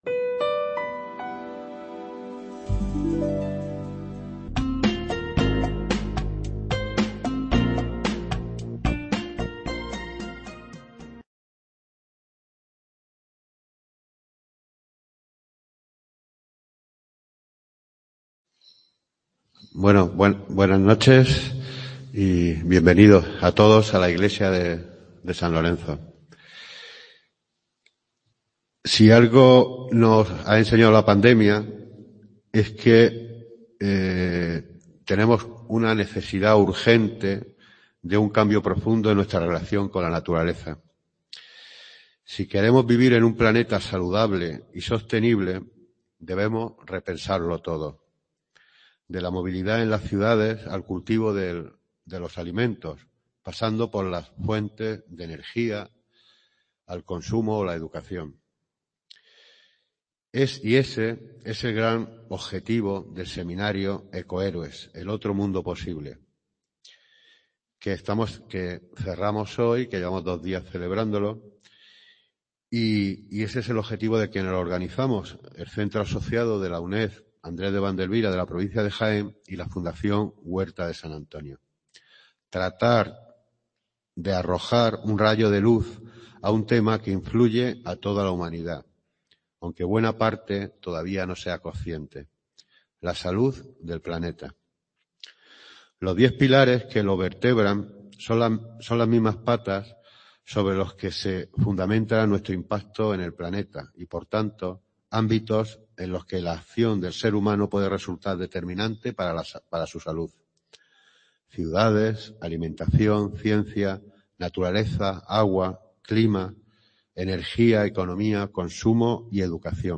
Conferencia de cinco charlas